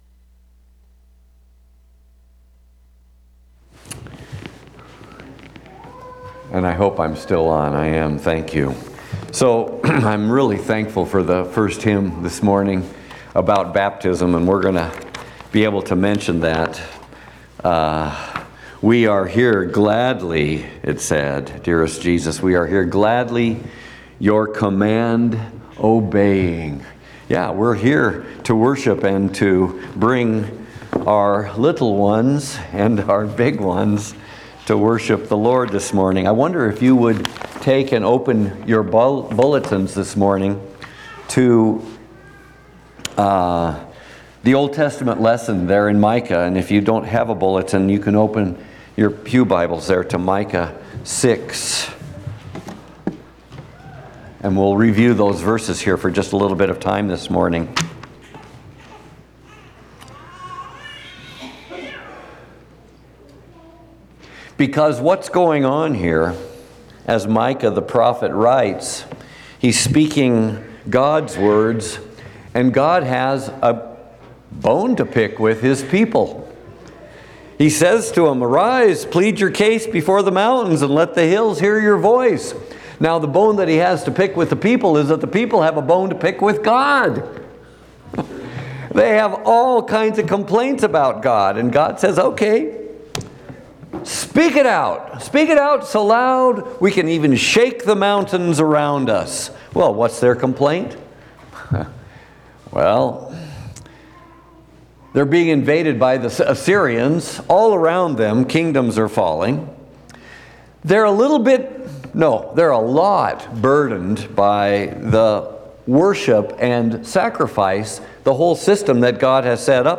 Fourth Sunday after the Epiphany
Trinity Lutheran Church, Greeley, Colorado Walk Humbly with Our God Feb 01 2026 | 00:25:03 1x 00:00 / 00:25:03 Subscribe Share RSS Feed Share Link Embed